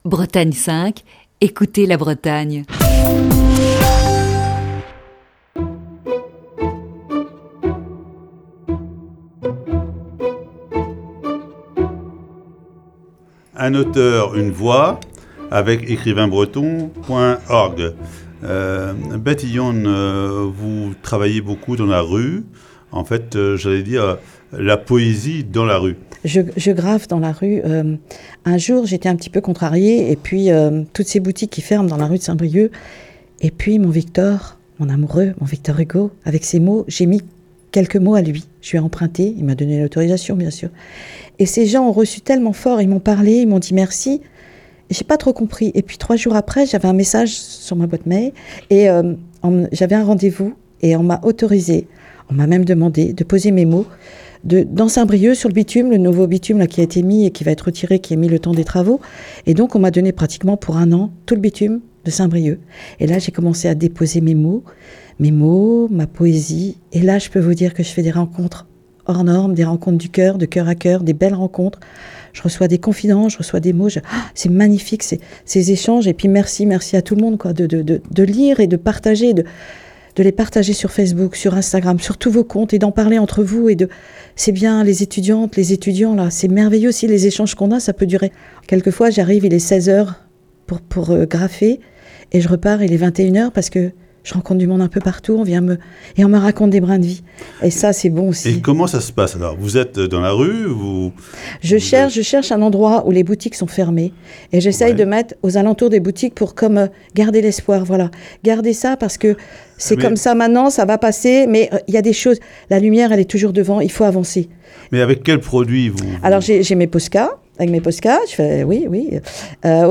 Ce mardi, deuxième partie de l'entretien.